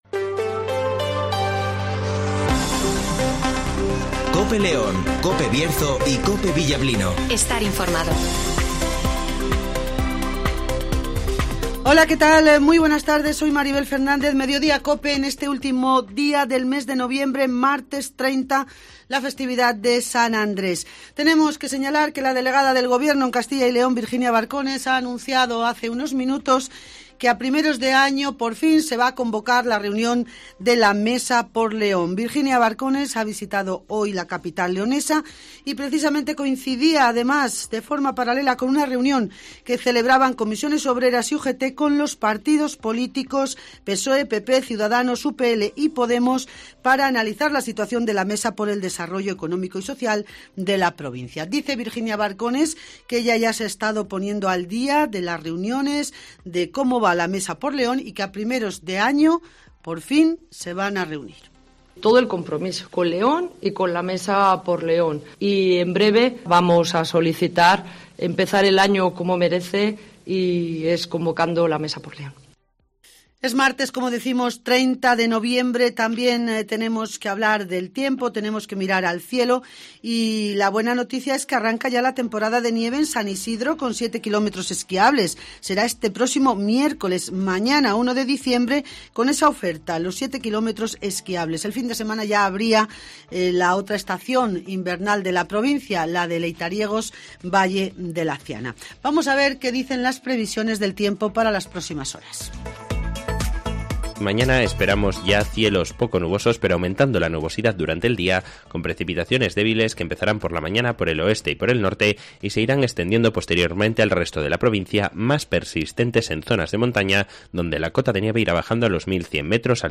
- Virginia Barcones delegada del gobierno en CyL